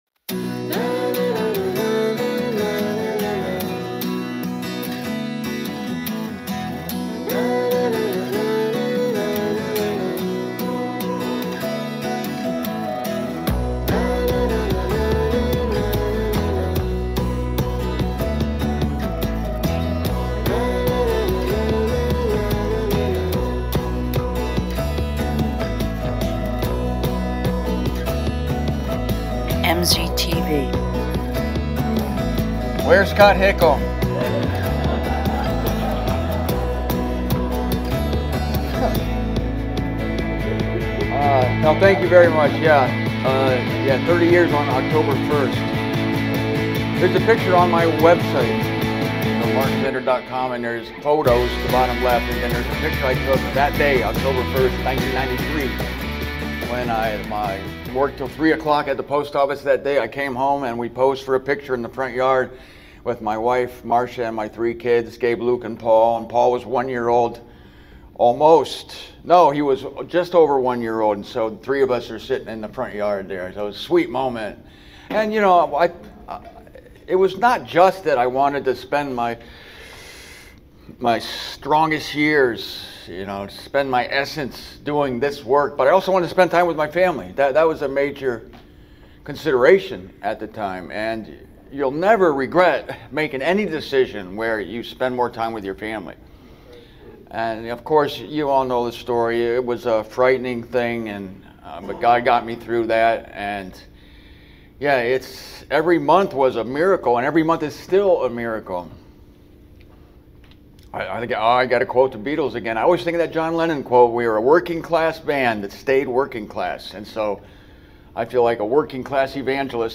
Here is a conversational address from the Des Moines conference from September of 2023. There is a little bit of everything here: personal testimony, an explanation of the coming evil and the election of a white horse rider, followed by a Q&A session.